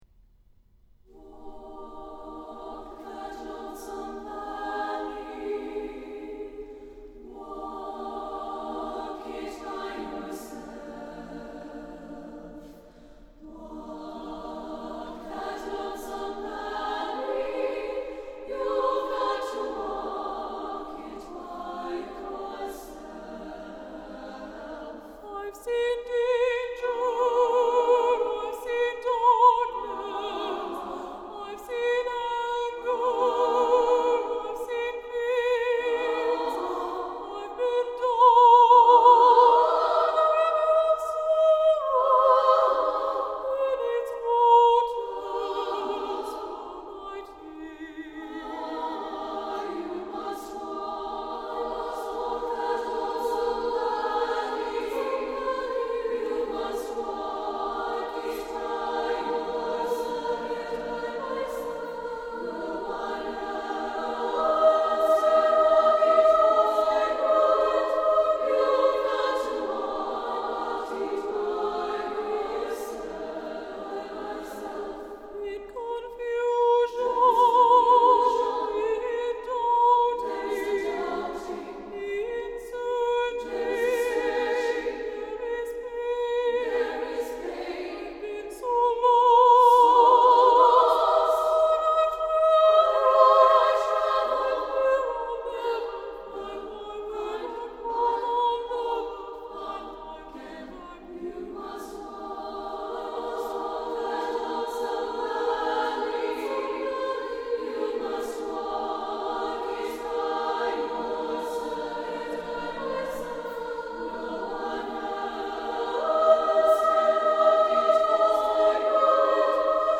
for SSAA Chorus (2000-02)
The Spirit of Women is a set of three songs for Women's Chorus, a cappella.
Solo voices often present the doubting and fears.